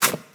step-1_1.wav